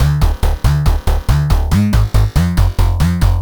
Index of /musicradar/future-rave-samples/140bpm
FR_Digga_140-D.wav